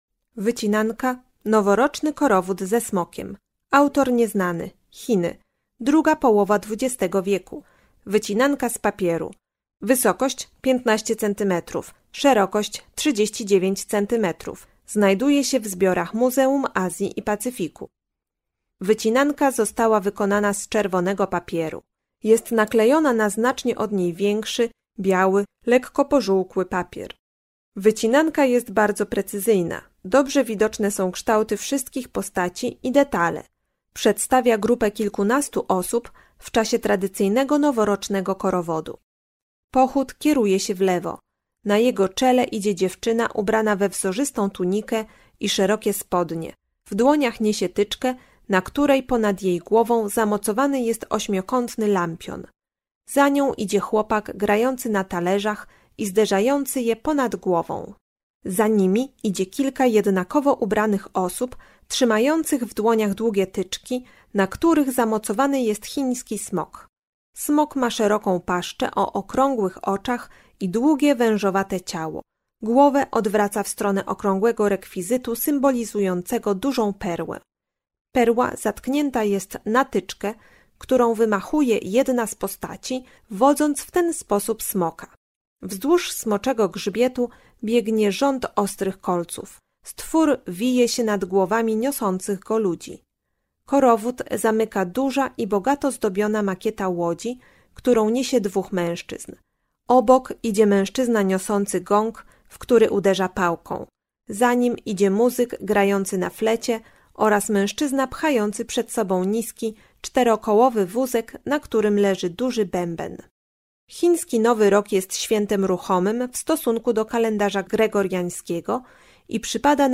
Audiodeskrypcje